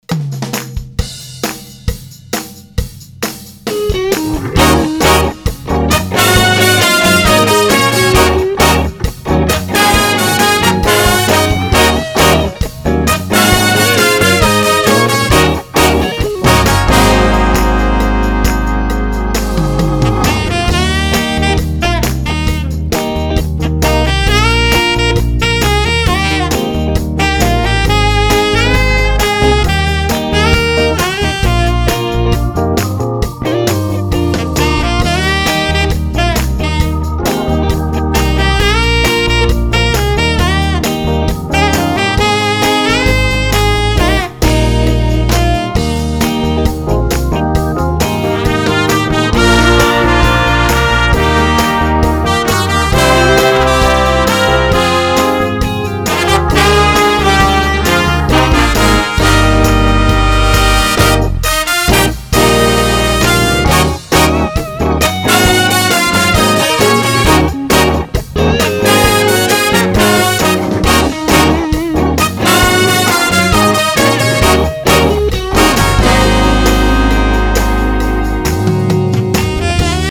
For now, here are some rough mix samples.